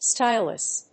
音節sty・lus 発音記号・読み方
/stάɪləs(米国英語), ˈstaɪl.əs(英国英語)/